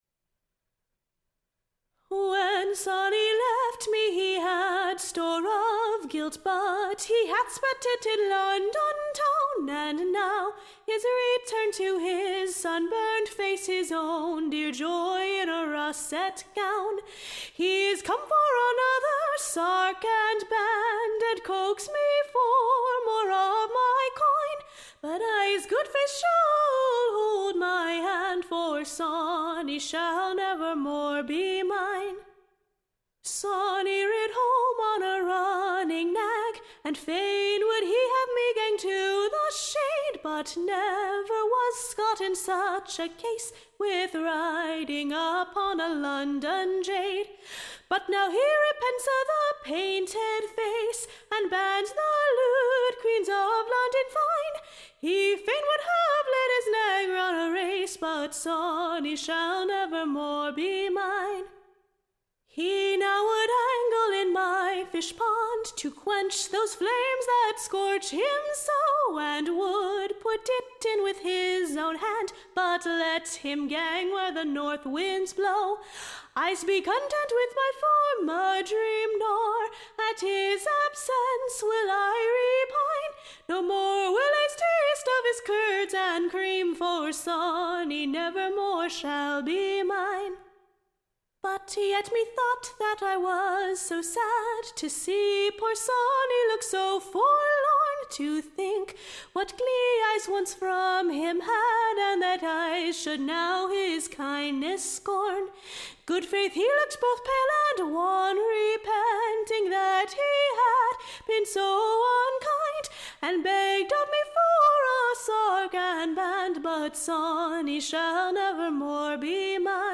Recording Information Ballad Title Jennies Answer to Sawny.
Tune Imprint To the Tune of, Sawney will ne'r be my Love again.